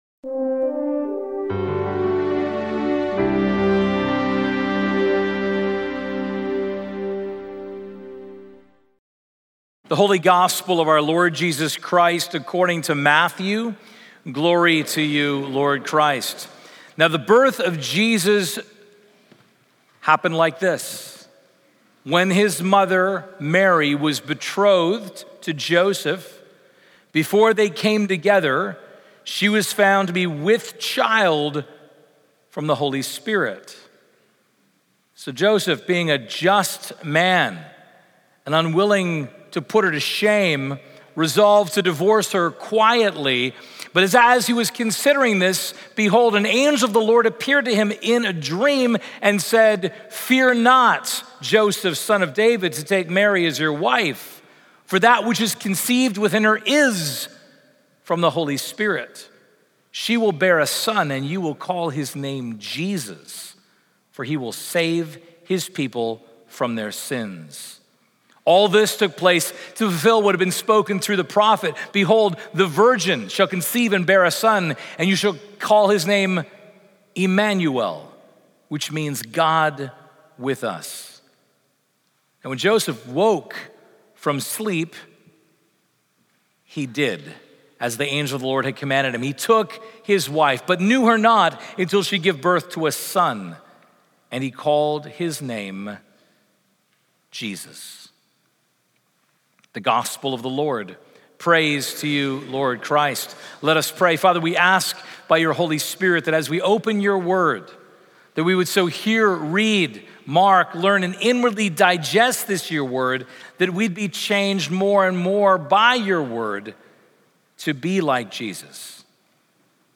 A podcast of recent sermons from Christ Church Plano.